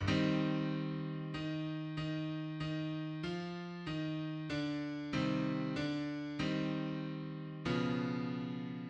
Dabei handelt es sich um eine Art Choral, der erstmals in der Ouvertüre erklingt und im Verlauf der Oper ungefähr vierzig Mal auftaucht.